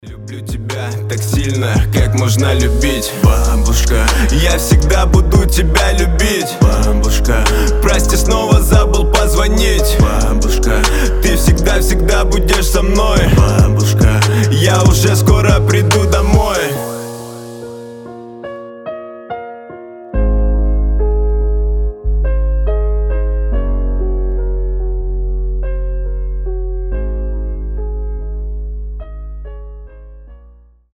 Лирика
Душевные
Рэп